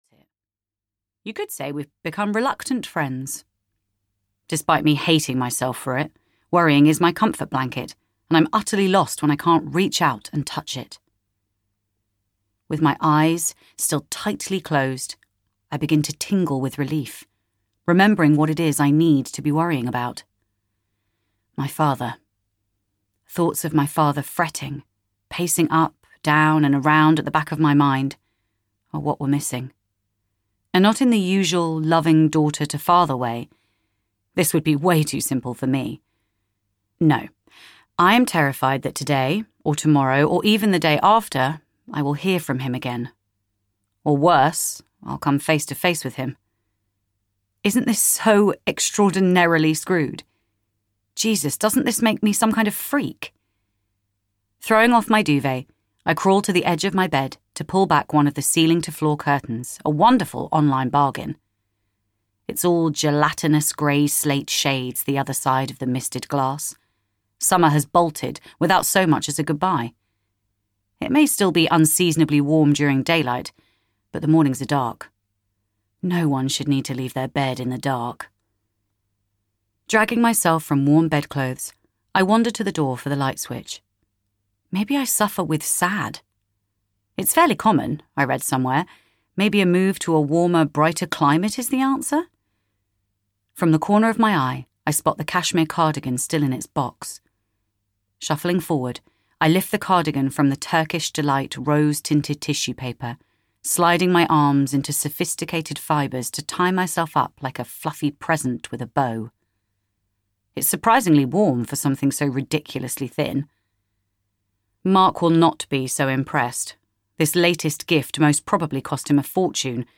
I Know You're There (EN) audiokniha
Ukázka z knihy